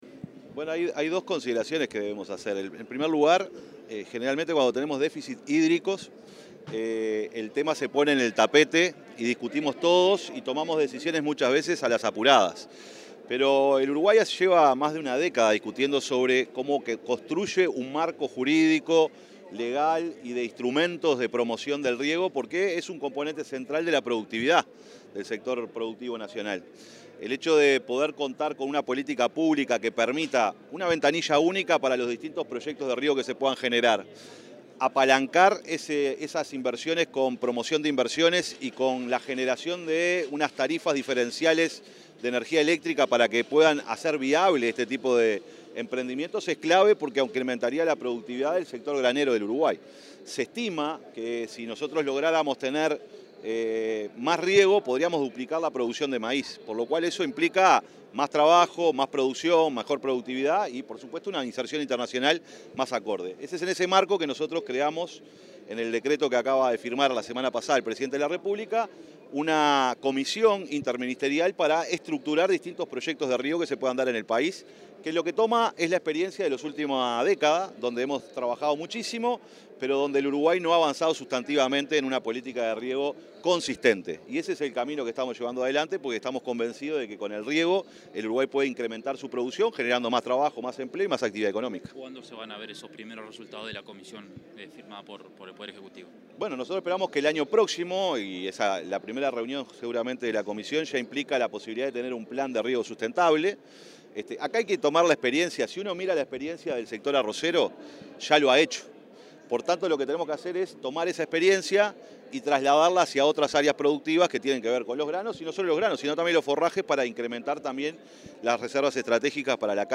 Declaraciones del secretario de la Presidencia, Alejandro Sánchez
El secretario de Presidencia, Alejandro Sánchez, realizó declaraciones a la prensa, en oportunidad de su disertación en la 120.ª Exposición